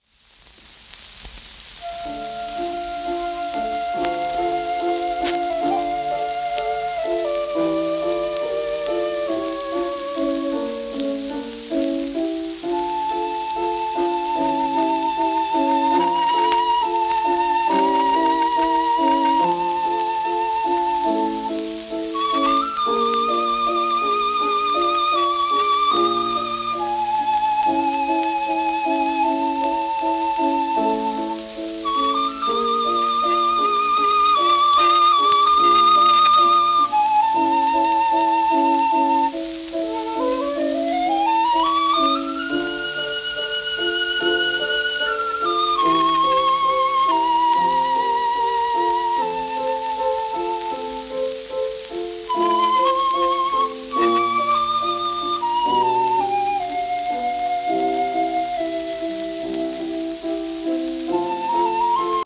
w/ピアノ
1927頃の録音